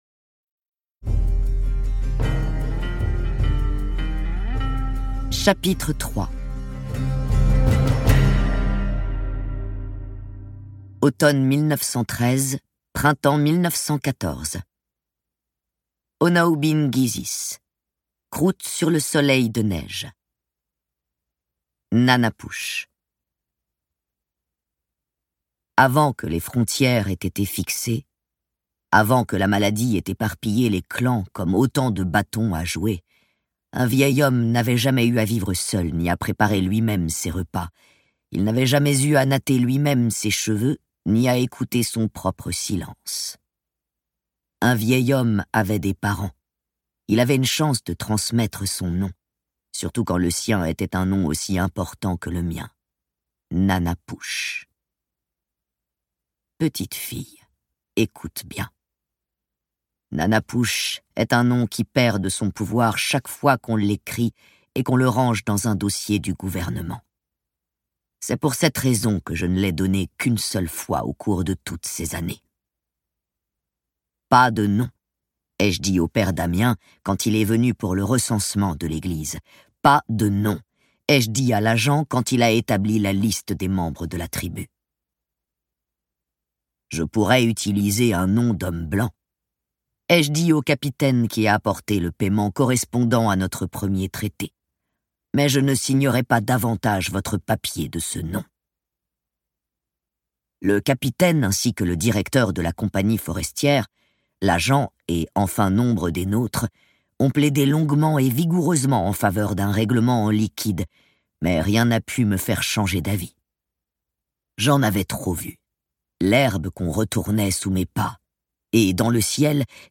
» - Page des libraires TTT (Très bien) - Télérama Ce livre audio est interprété par une voix humaine, dans le respect des engagements d'Hardigan.